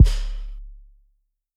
Kick (13).wav